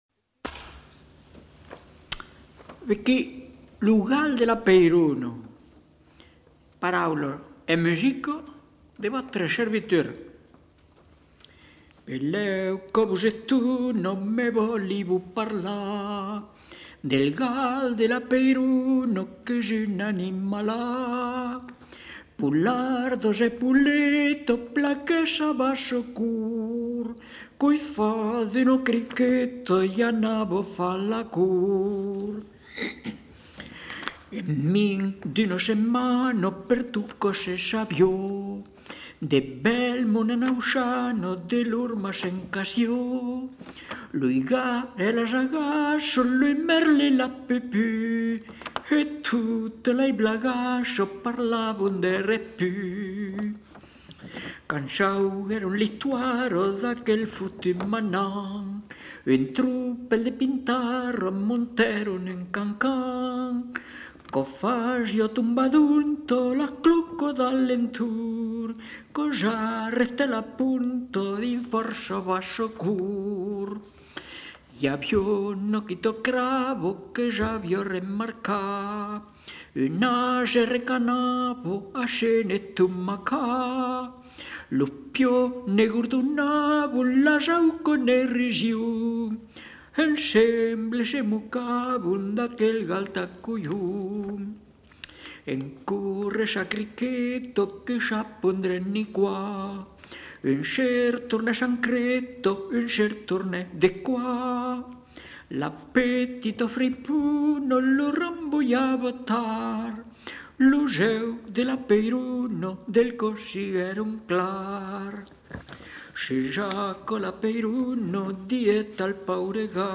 Aire culturelle : Périgord
Genre : chant
Effectif : 1
Production du son : chanté